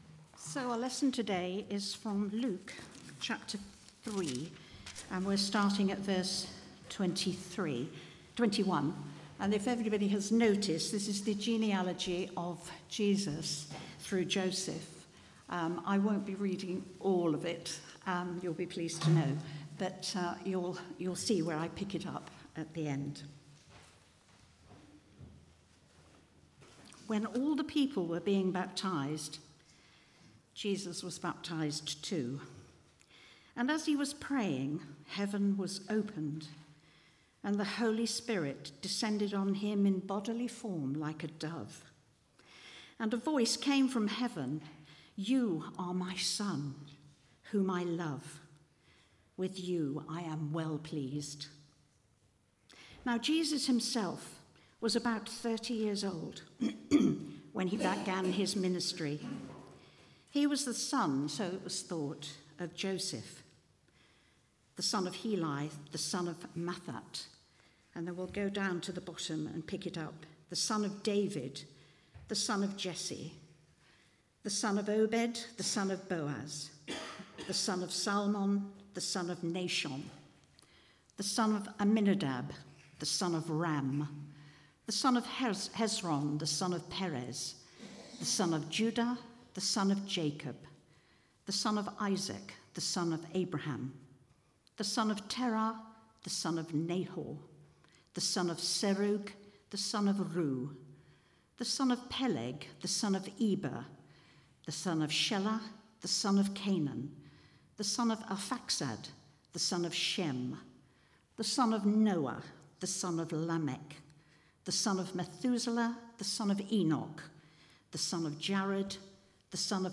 Media for Arborfield Morning Service
Sermon